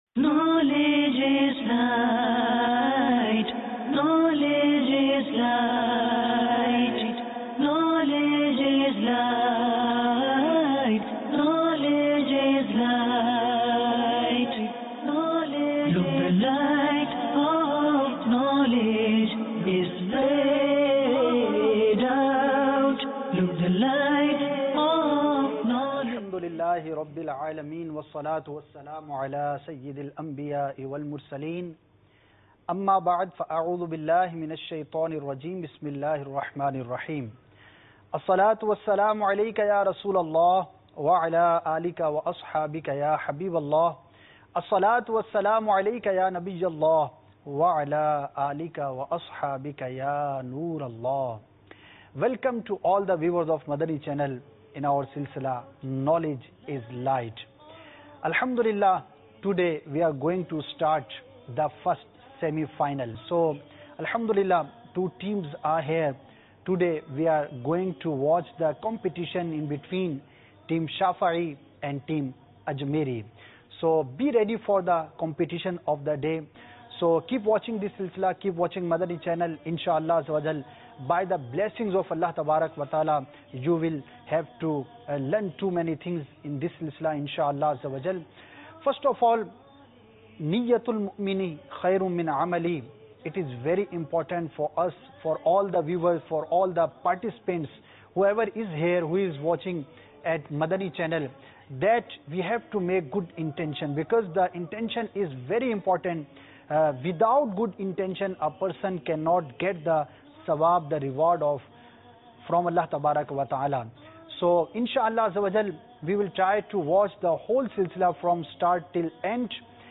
Knowledge Is Light Ep 10 - Semi Final - Shafiee Vs Ajmairi Jul 27, 2016 MP3 MP4 MP3 Share This edifying video comprises a very inspirational Islamic Quiz Competition viz. Knowledge Is Light Episode No: 10 - Semi Final comprising (Madani pearls of wisdom and Islamic Question & Answers in English language) between Shafiee vs. Ajmairi teams held in Bab-ul-Madinah Karachi Pakistan.